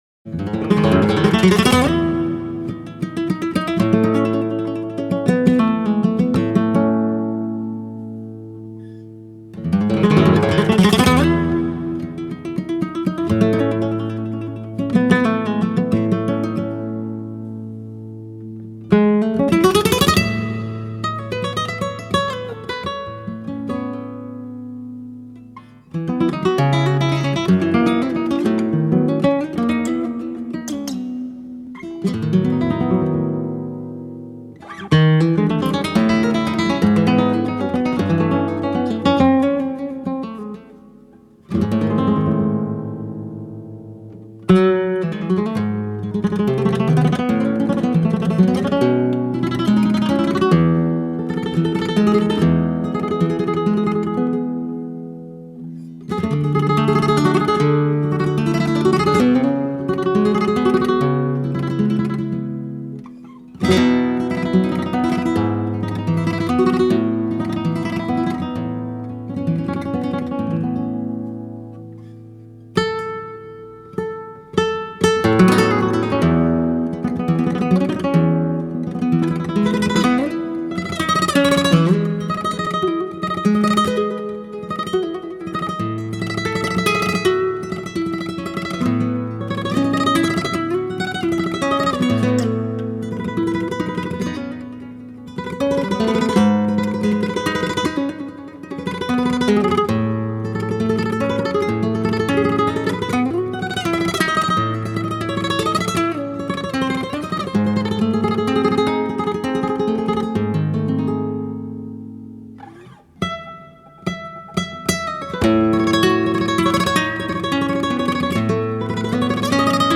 minera)) — composition et guitare